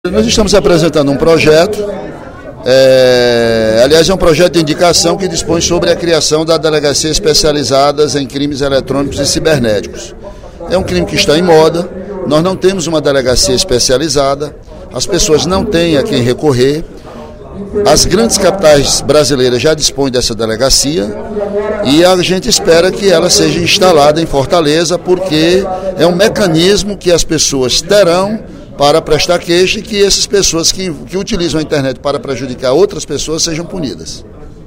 O deputado Ely Aguiar (PSDC) apresentou nesta quarta-feira (03/04), durante o primeiro expediente, projeto de indicação de sua autoria sugerindo a criação de uma delegacia especializada em crimes eletrônicos e cibernéticos em Fortaleza.